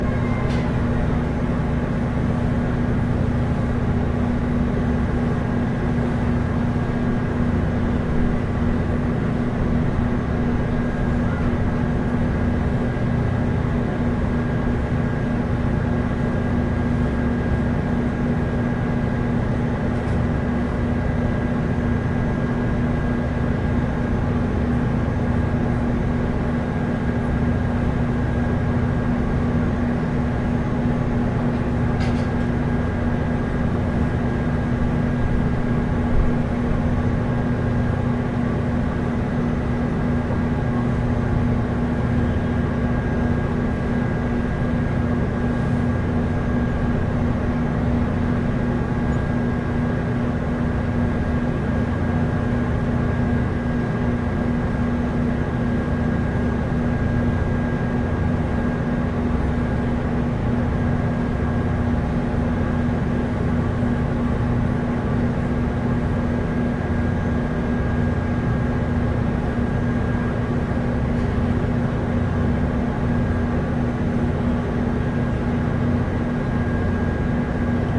23艘VHFerry中船 - 声音 - 淘声网 - 免费音效素材资源|视频游戏配乐下载
录音是在一艘渡船的甲板上录制的，从大陆到一个岛屿。